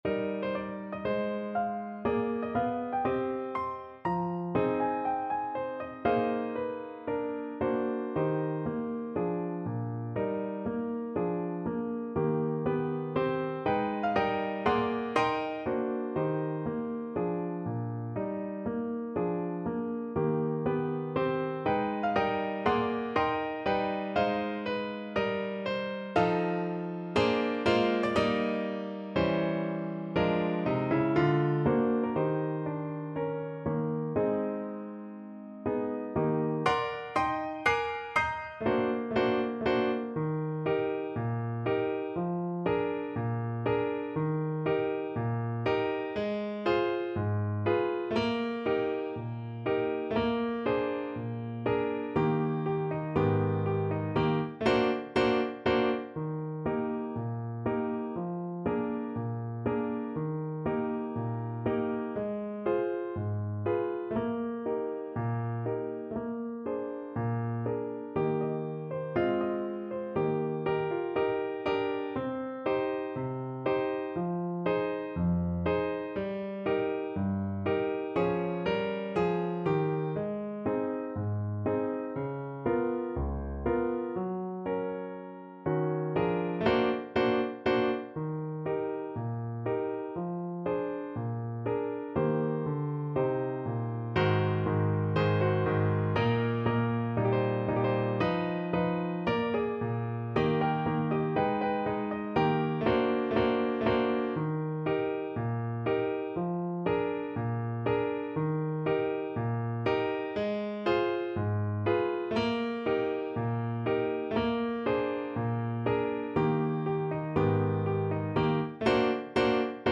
~ = 120 Moderato